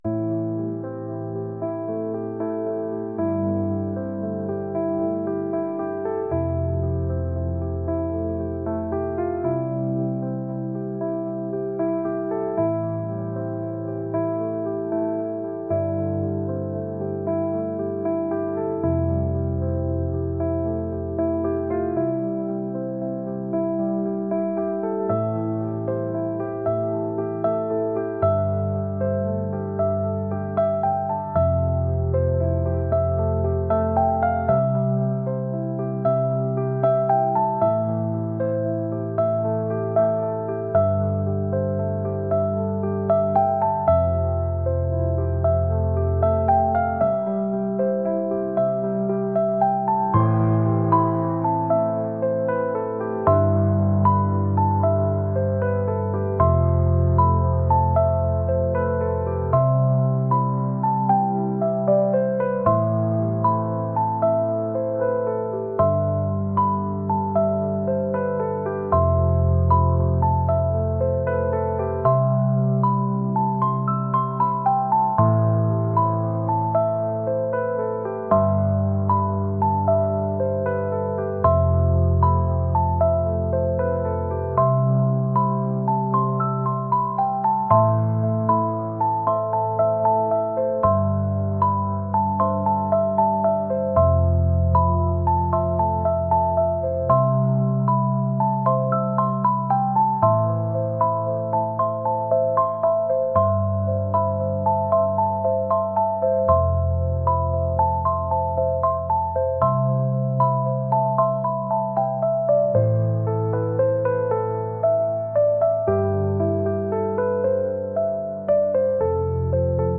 ambient | pop | cinematic